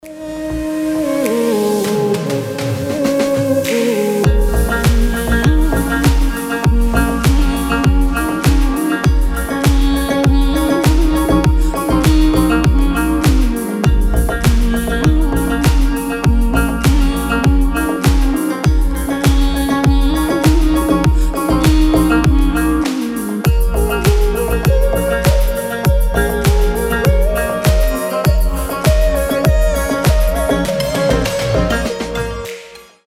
• Качество: 320, Stereo
восточные мотивы
женский голос
без слов
красивая мелодия
Стиль: deep house, oriental house